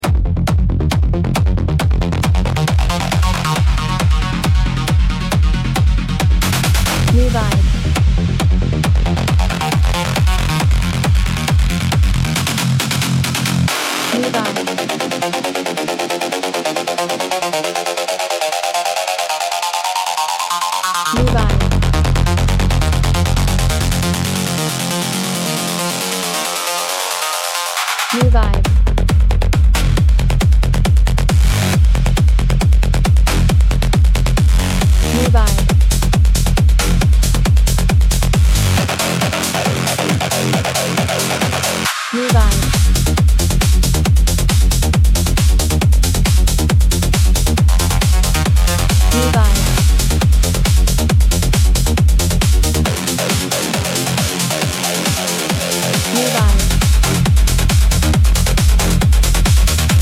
Genre: Rave